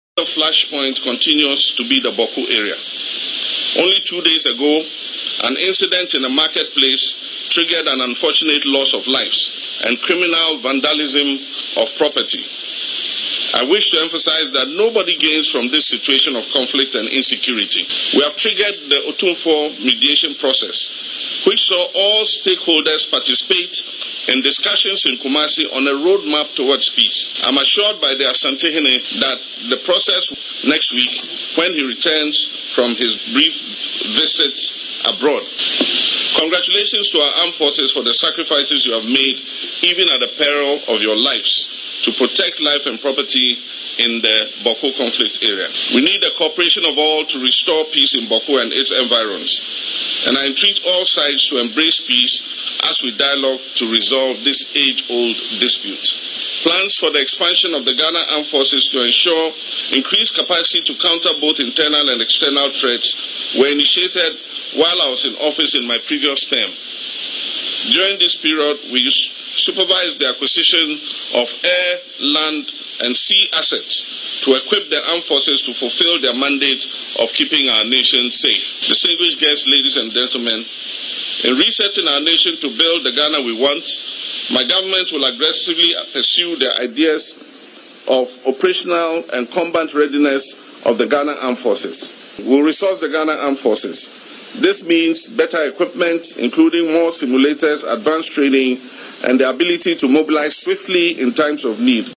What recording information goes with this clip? Speaking at a graduation ceremony at the Ghana Military Academy on Friday, April 11, the President said the Asantehene had assured him of his commitment to reconvene stakeholders and continue the peace dialogue upon his return from a brief visit abroad.